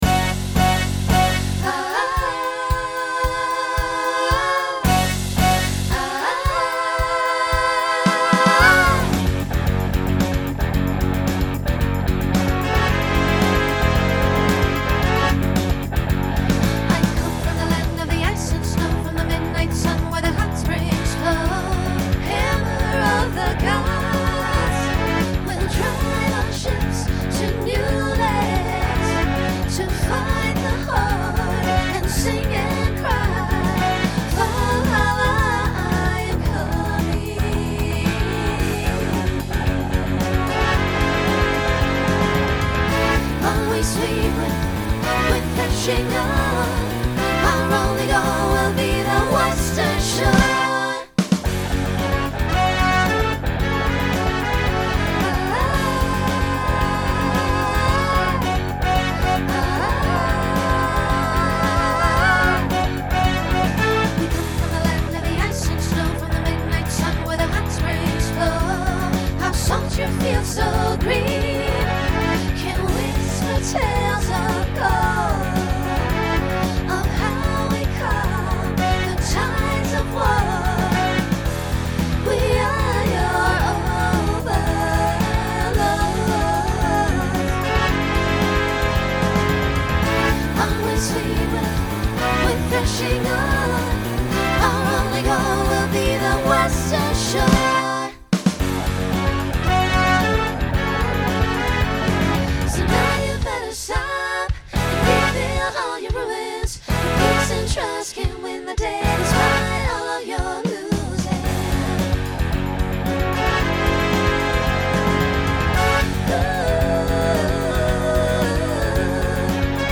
Genre Rock Instrumental combo
Voicing SSA